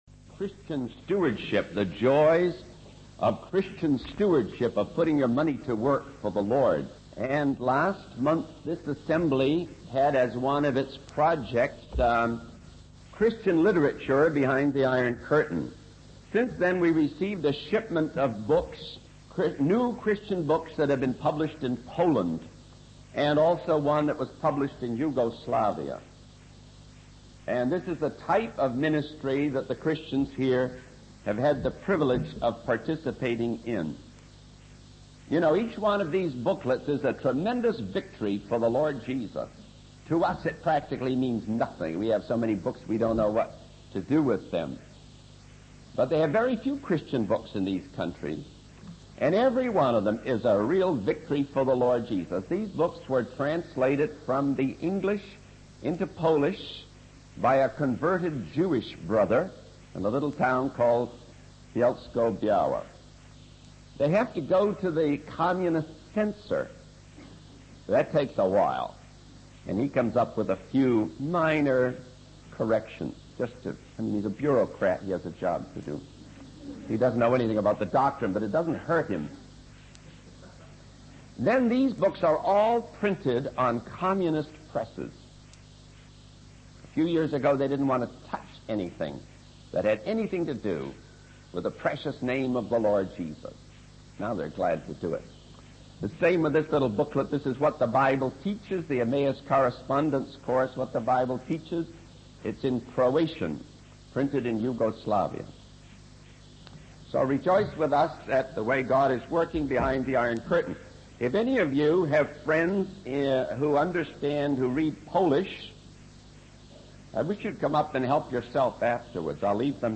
In this sermon, the preacher focuses on the story of Jesus healing a blind man and the different responses to his miracles. He highlights that there are two classes of people in the world: those who are blind and willing to admit it, and those who are blind but refuse to acknowledge it.